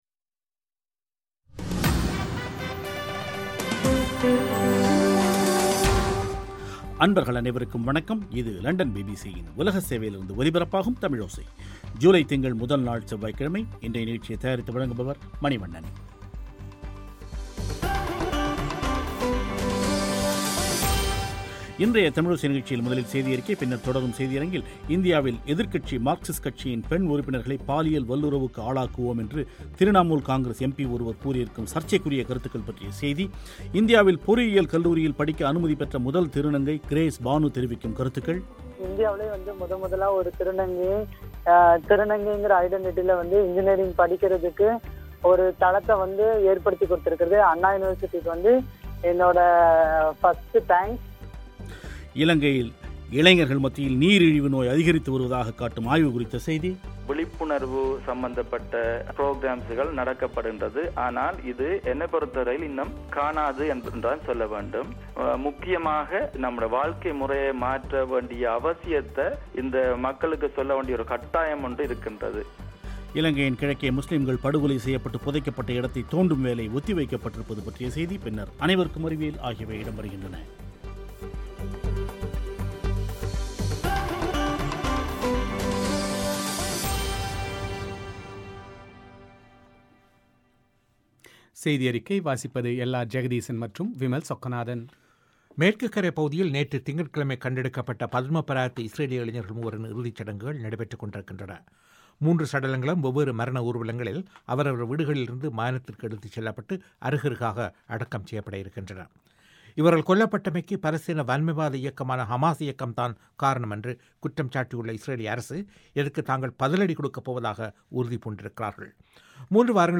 இன்றைய தமிழோசை நிகழ்ச்சியில் முதலில் செய்தி அறிக்கை,பின்னர் தொடரும் செய்தி அரங்கில்